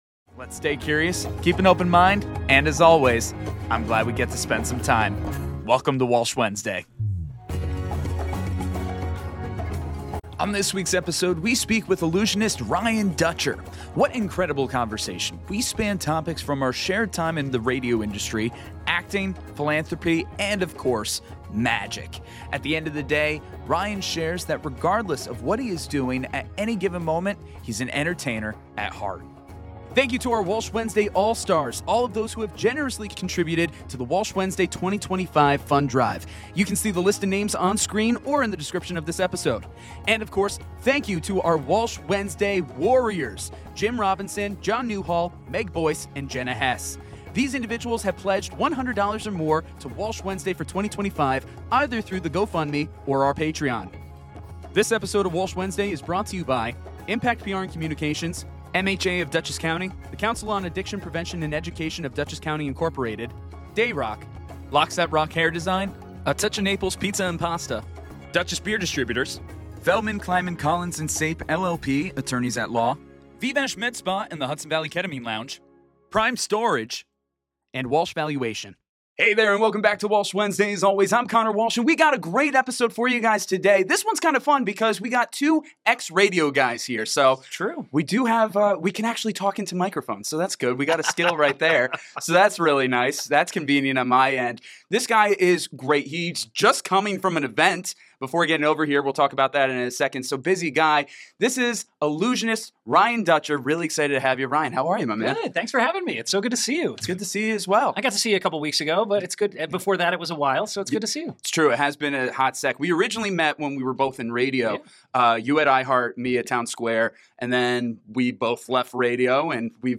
What an incredible conversation! We span topics from our shared time in the radio industry, acting, philanthropy, and of course, MAGIC!